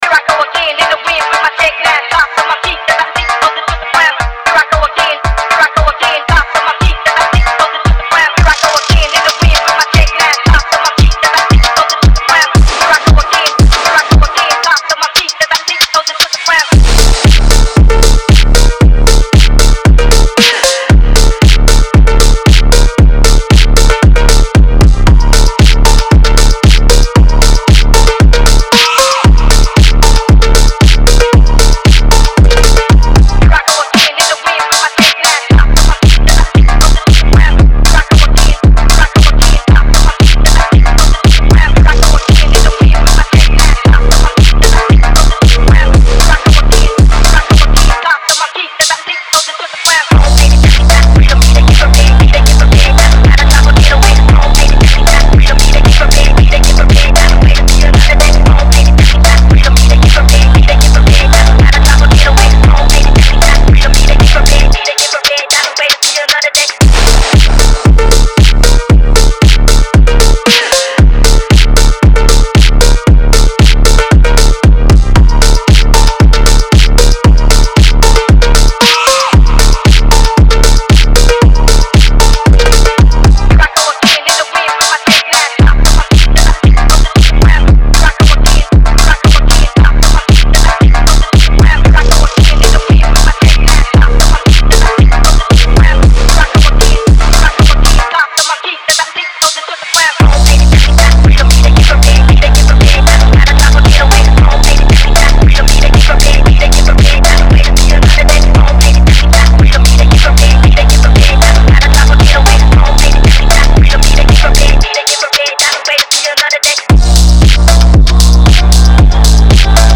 Басовая быстрая музыка
Фонк музыка
басовая музыка